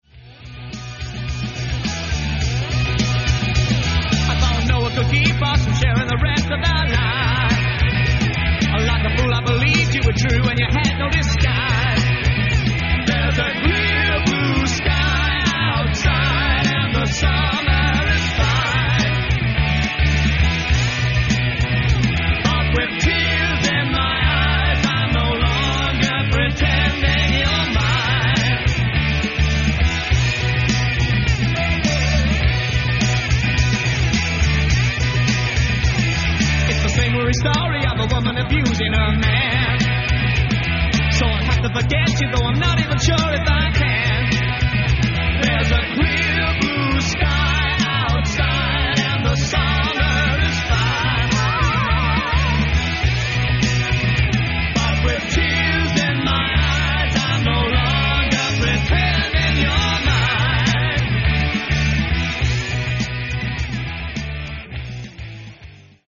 Lansdowne Studios, London
Organ, Piano, Guitar & Vocal
Drums